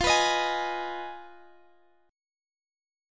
Listen to Fm7b5 strummed